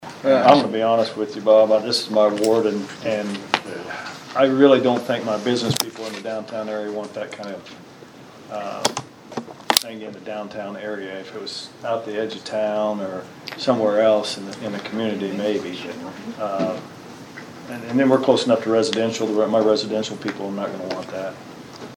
There was a long discussion at Monday’s Vandalia City Council meeting on the possible sale of the former First Baptist Church building in downtown Vandalia.